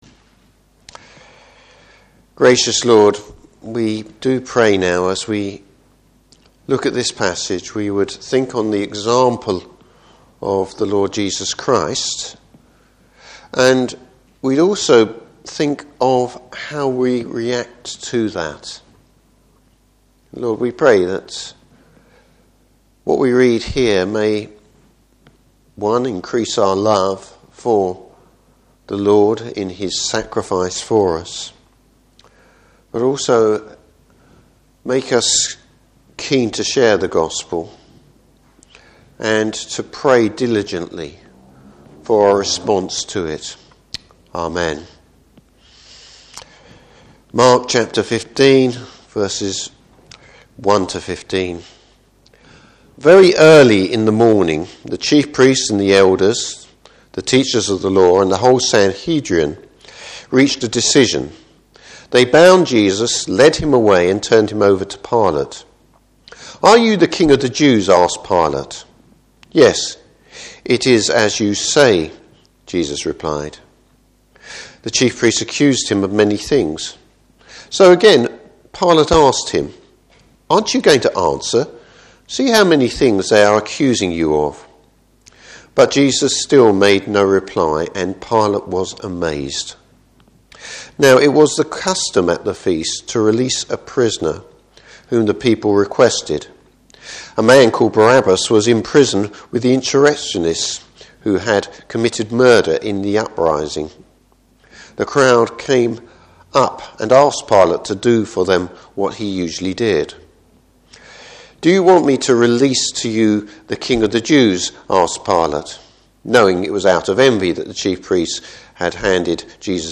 Passage: Mark 15:1-15. Service Type: Morning Service Just who’s on trail here?